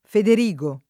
Federico [feder&ko] (tosc. o lett. Federigo [